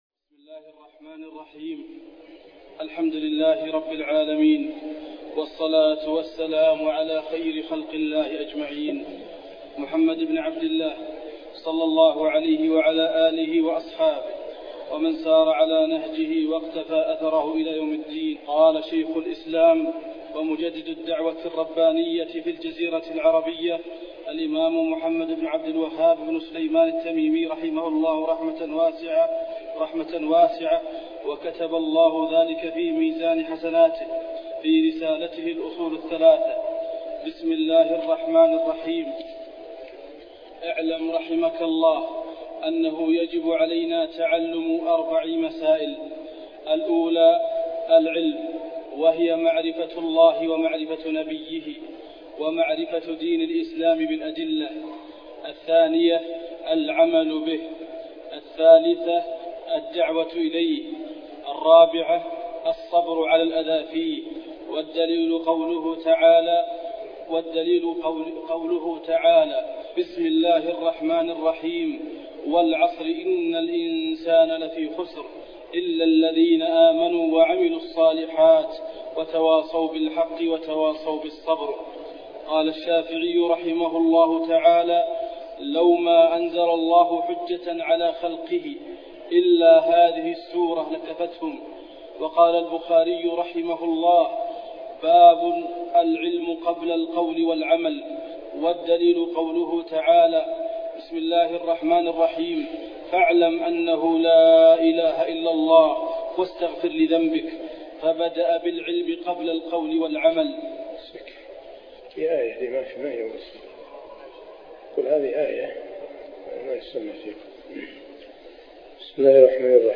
تفاصيل المادة عنوان المادة الدرس (1) شرح الأصول الثلاثة تاريخ التحميل الأحد 29 يناير 2023 مـ حجم المادة 25.68 ميجا بايت عدد الزيارات 216 زيارة عدد مرات الحفظ 103 مرة إستماع المادة حفظ المادة اضف تعليقك أرسل لصديق